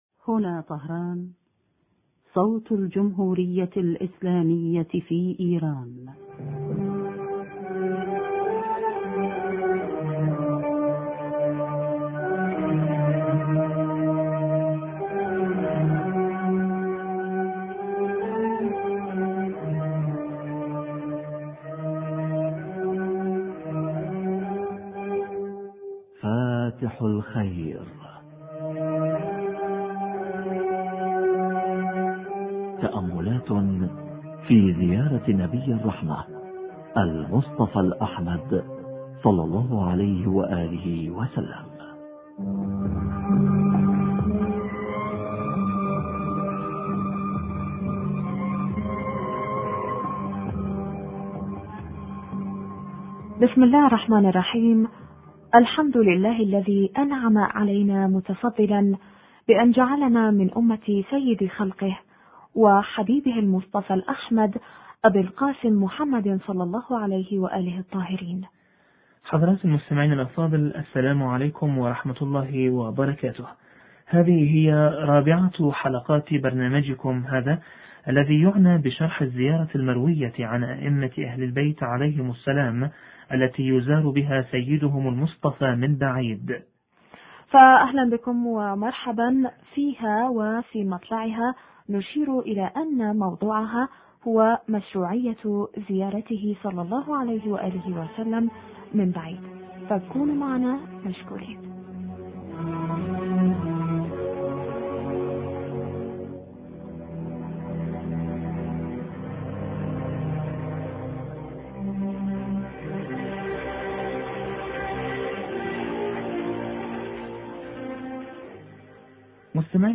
اجماع المسلمين على مشروعية زيارة النبي(ص) كجزء من الصلاة حوار